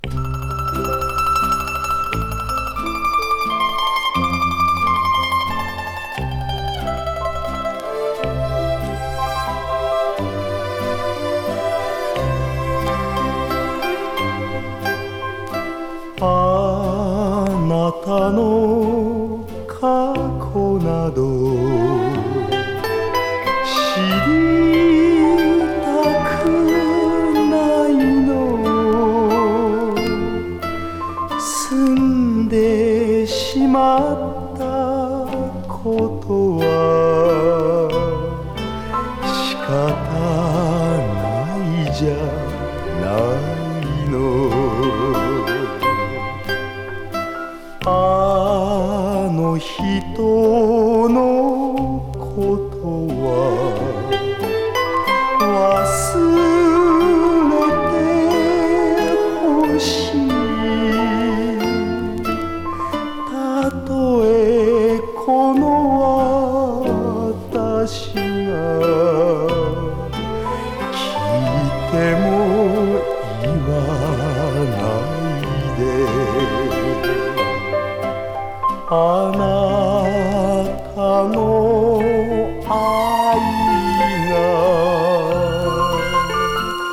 スタンダード・ポップス・ナンバー
途轍もないムーディーさが漂ってます。